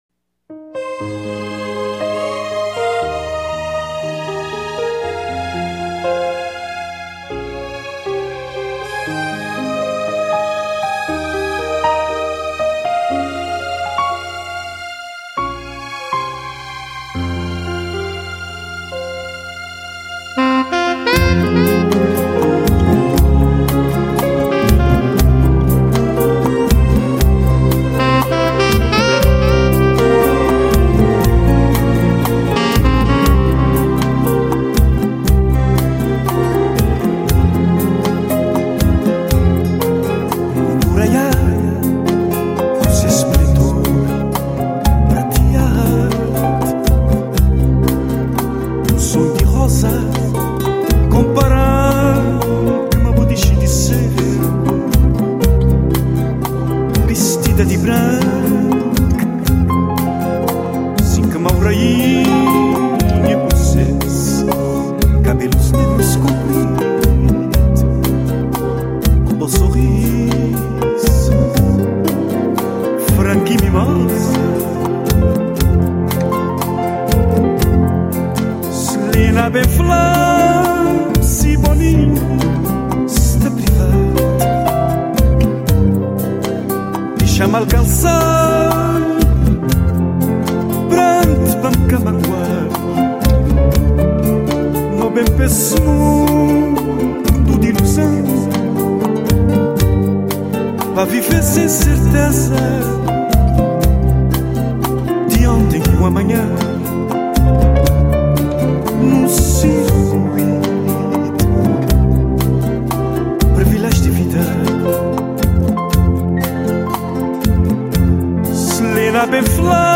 morna